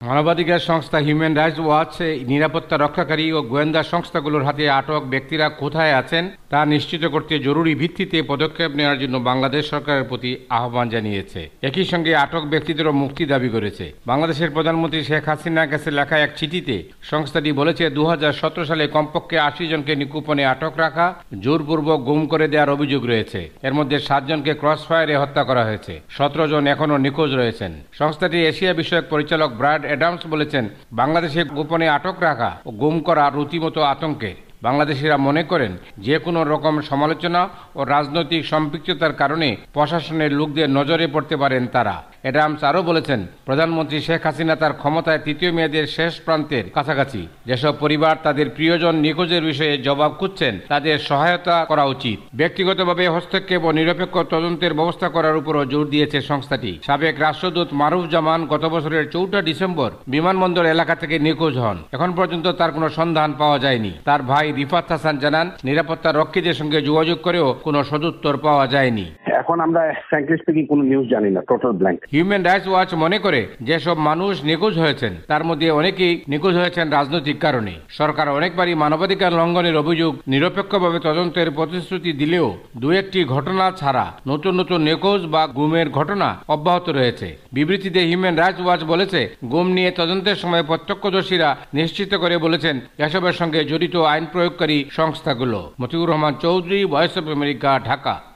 ঢাকা থেকে